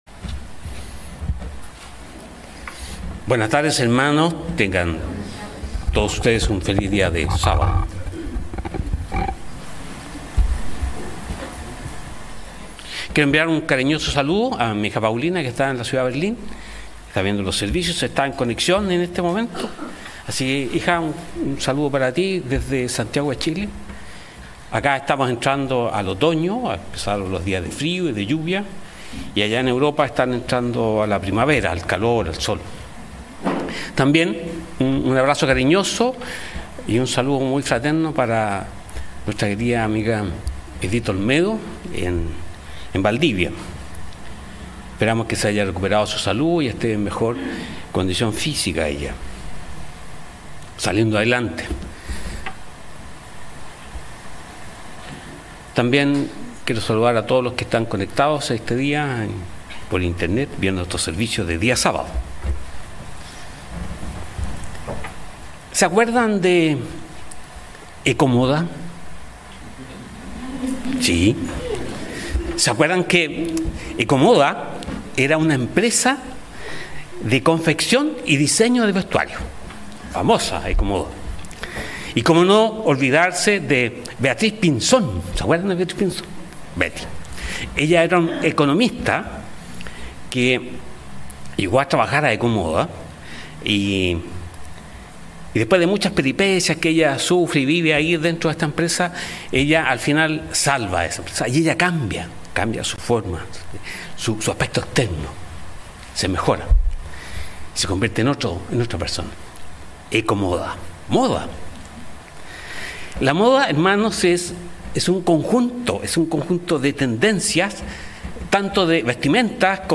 Sermones
Given in Santiago